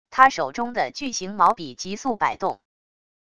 他手中的巨型毛笔极速摆动wav音频